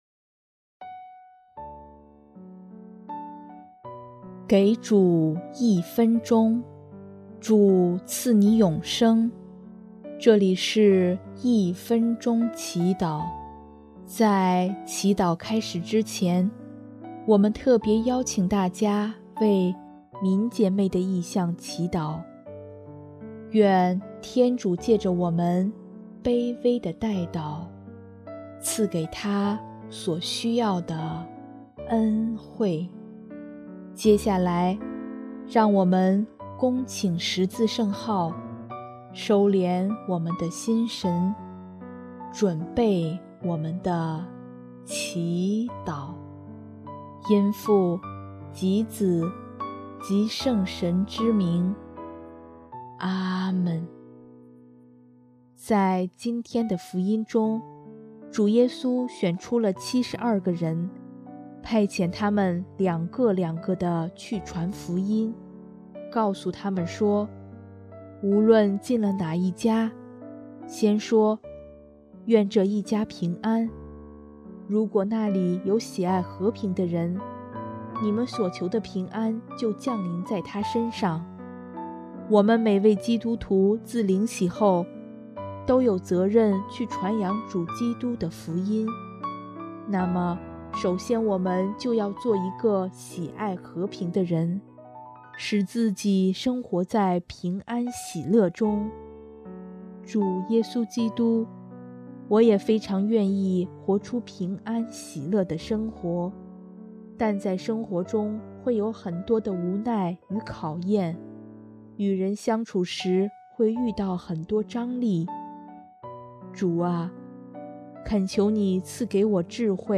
【一分钟祈祷】|1月26日 遵从主的教导，活出平安喜乐的生活